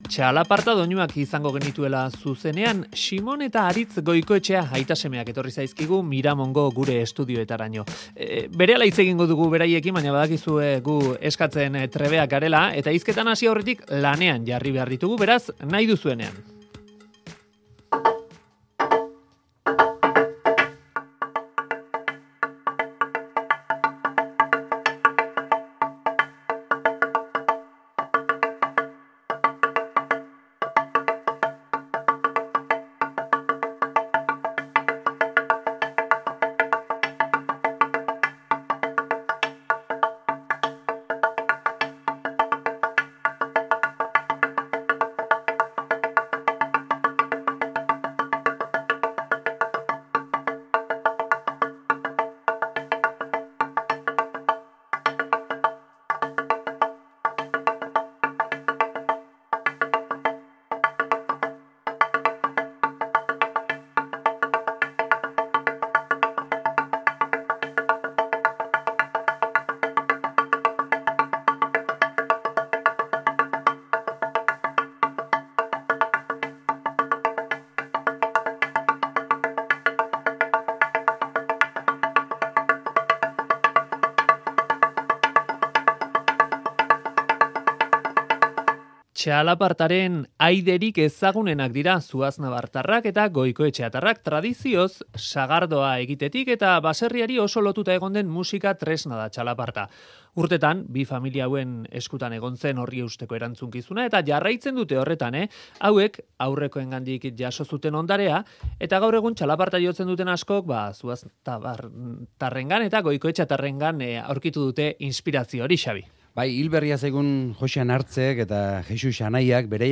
txalapartak ttakun eta ttakun jarraitzen du